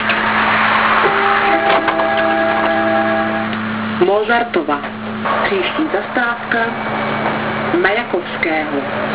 Hlášení zastávek a mimořádností: